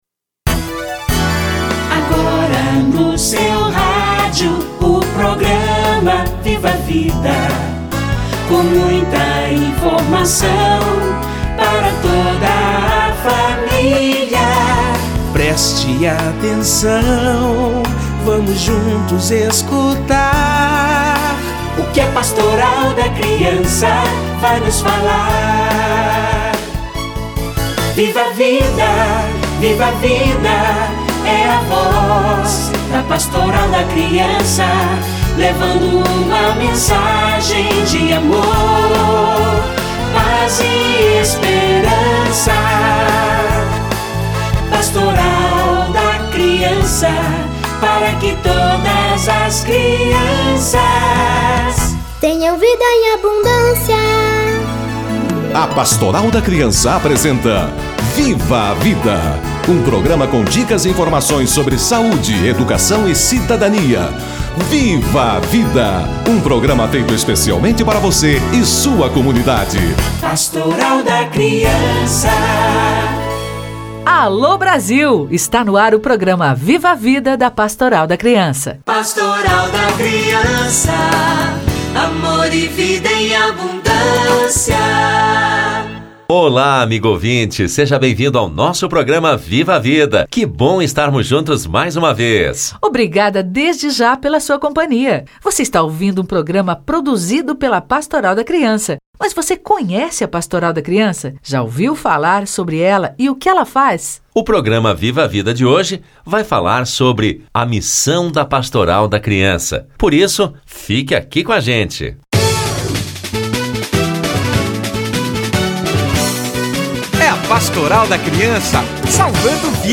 Missão da Pastoral da Criança - Entrevista